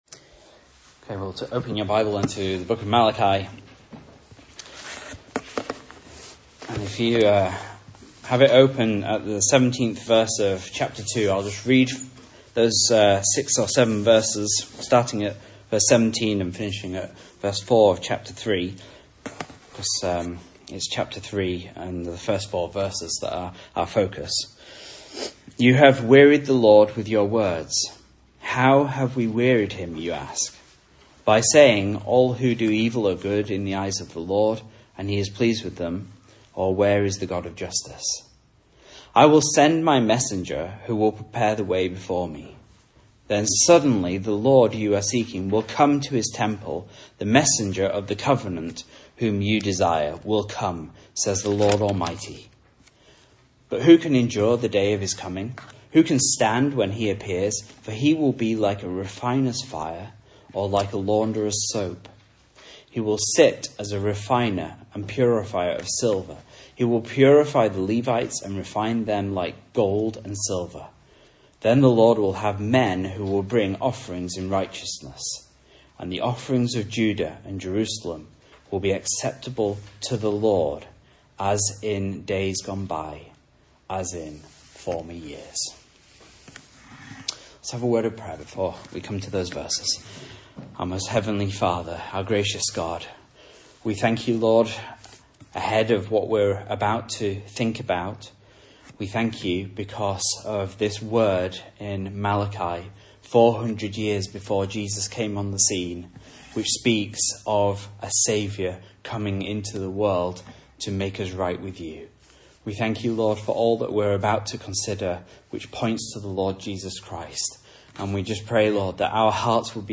Message Scripture: Malachi 3:1-4 | Listen